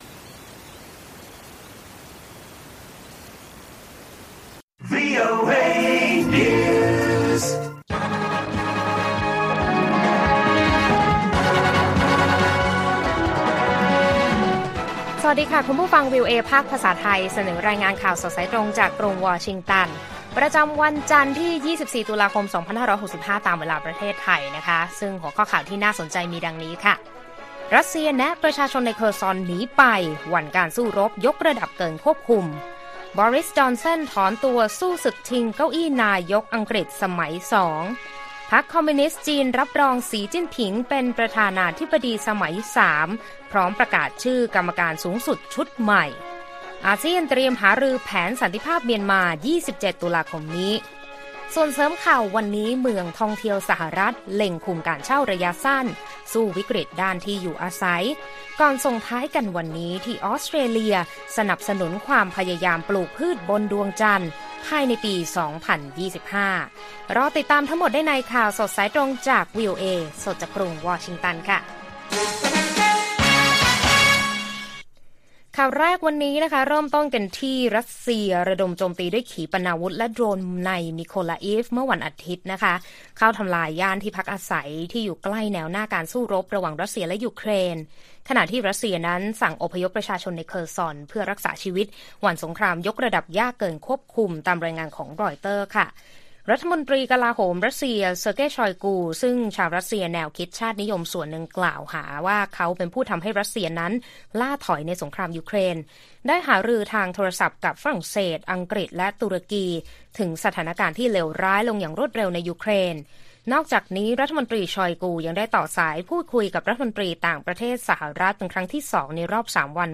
ข่าวสดสายตรงจากวีโอเอไทย วันจันทร์ ที่ 24 ต.ค. 2565